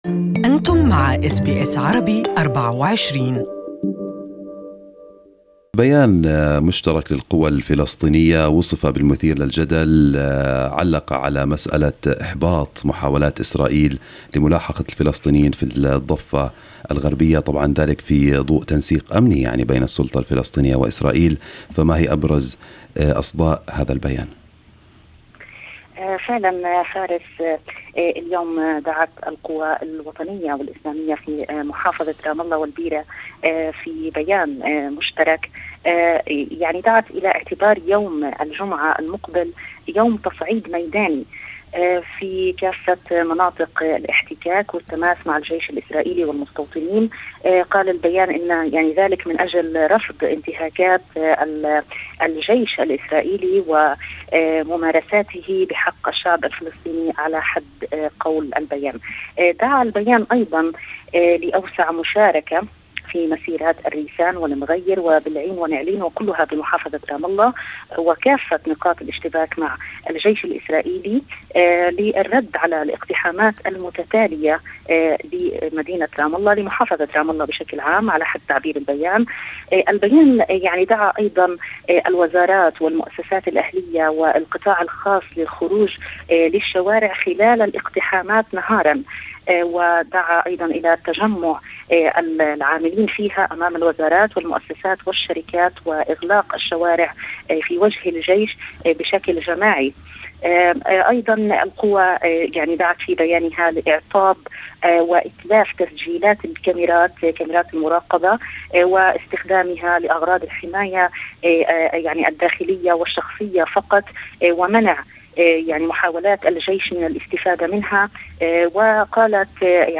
SBS Arabic